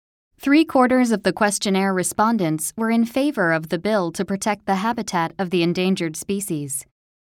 (B) 英語例文音声